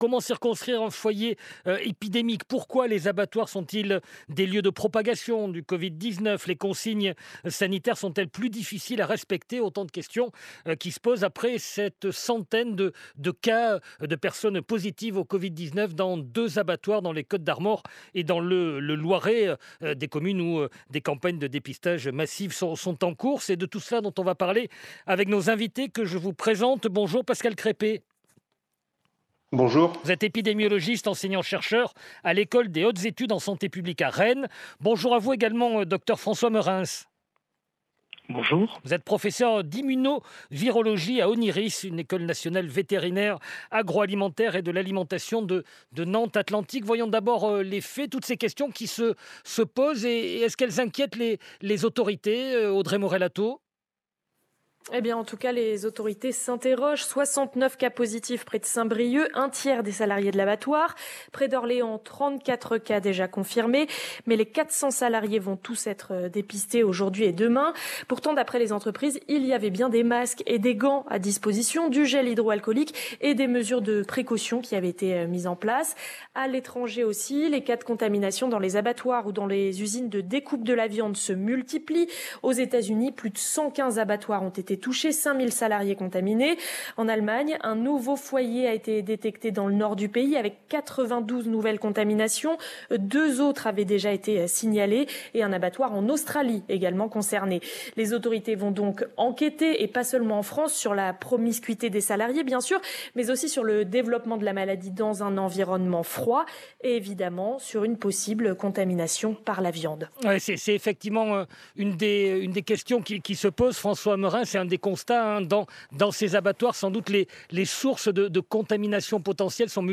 Several experts have been contacted by France Info to interact on these questions.